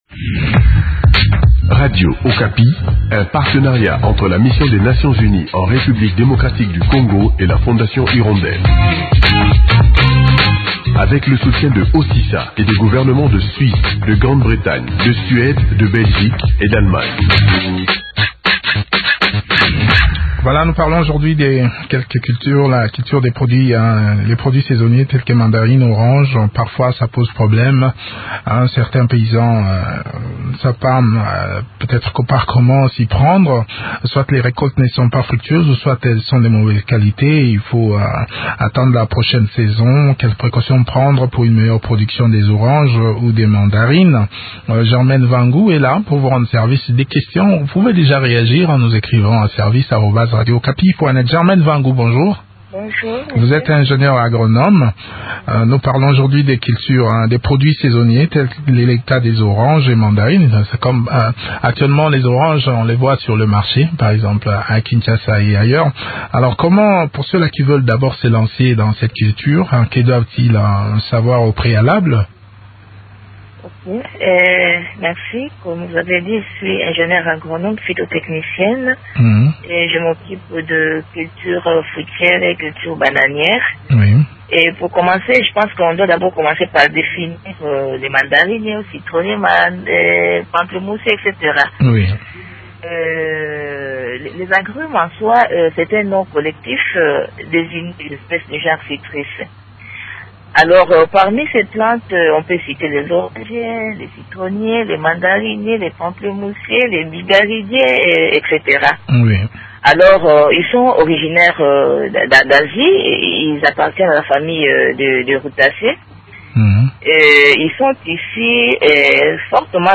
ingénieur agronome.